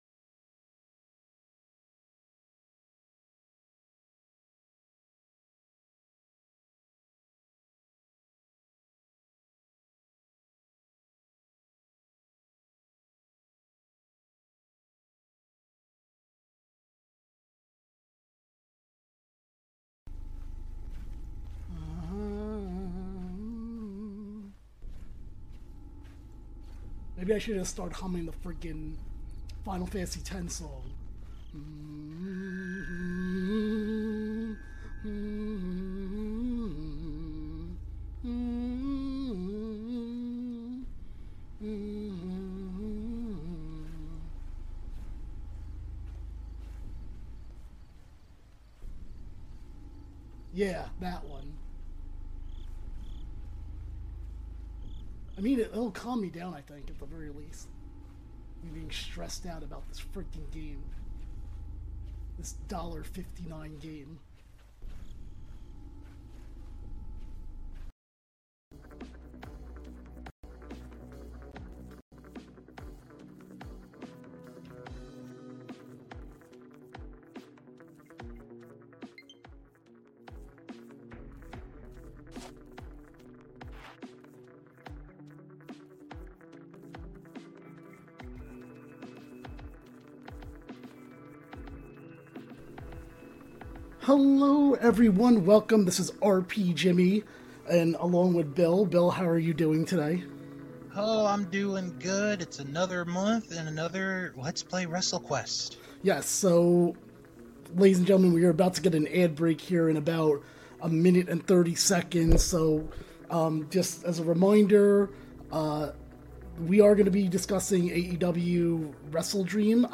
This episode, which was streamed live on Twitch, has the guys discussing AEW Wrestledream, other stuff going on in wrestling and of course playing Wrestlequest.